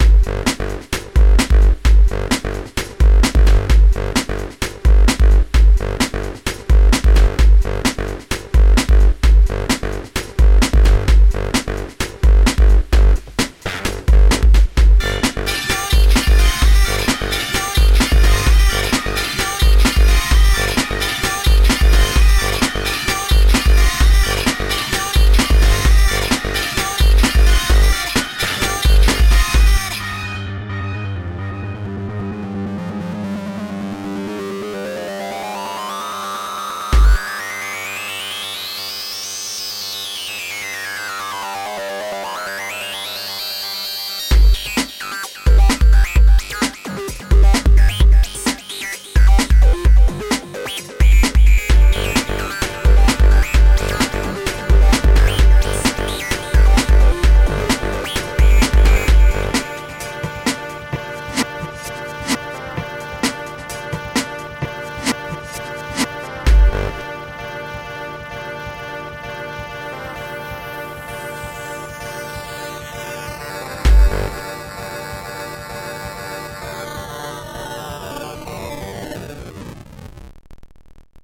A few seconds into the first kickdrum hit and bass sound and I’m like “yup, someone likes The Prodigy!”.
You’ve got some cool sounds in there, keep it up.
I was hoping the breaks would kick off hard, cos it was such a promising intro.
breakbeat.mp3